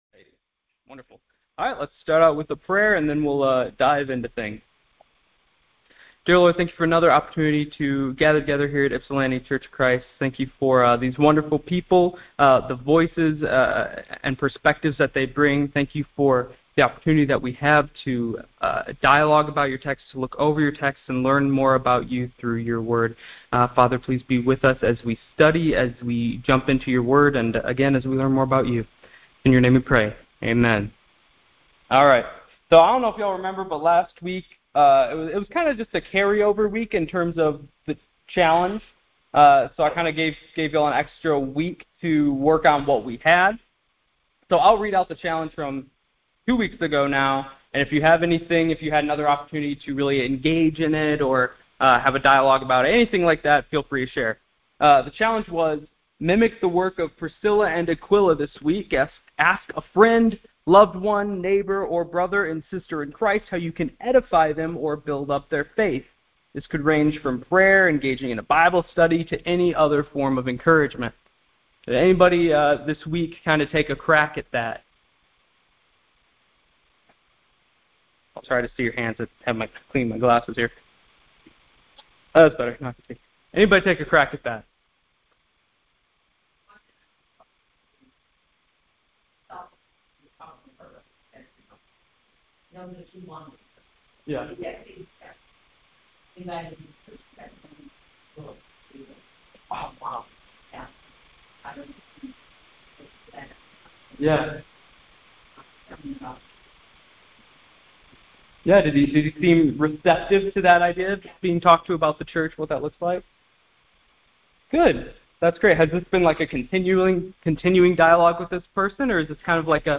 Bible Study 7.9.25